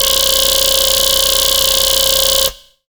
SGLBASS  2-R.wav